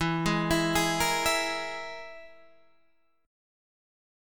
EMb5 chord {x 7 8 9 9 6} chord